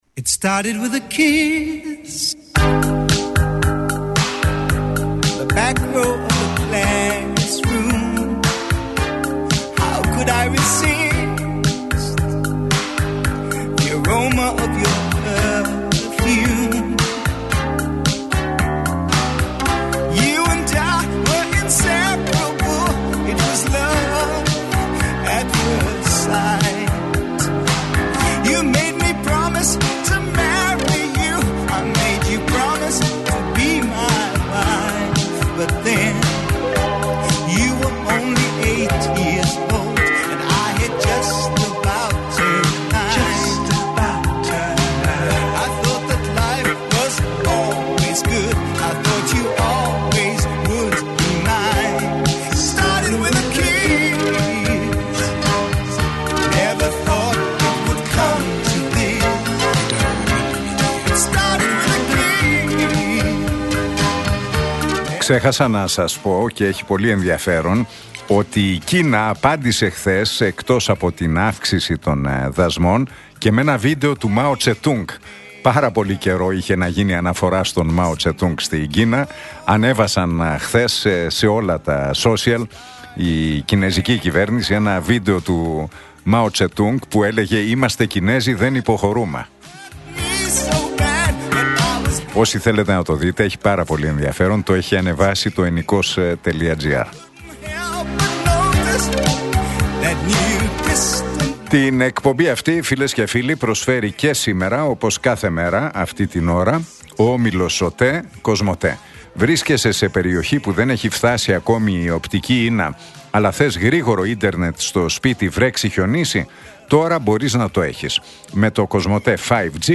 Ακούστε την εκπομπή του Νίκου Χατζηνικολάου στον ραδιοφωνικό σταθμό RealFm 97,8, την Πέμπτη 10 Απριλίου 2025.